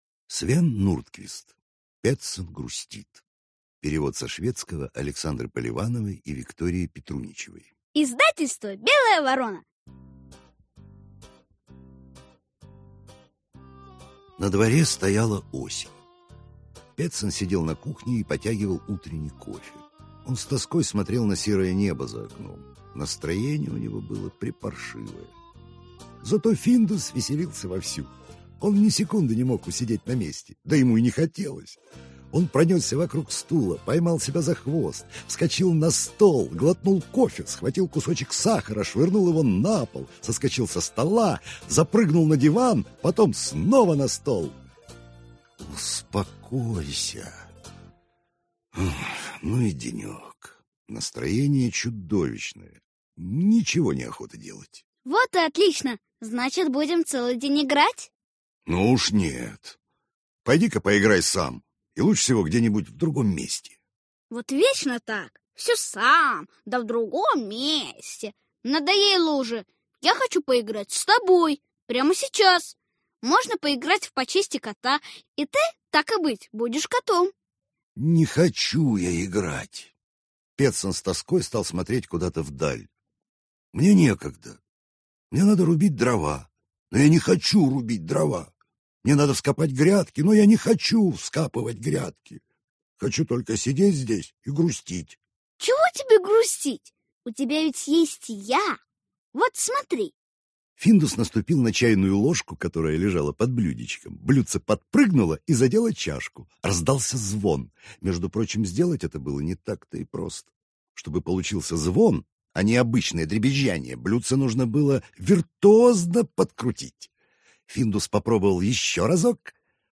Аудиокнига Петсон грустит | Библиотека аудиокниг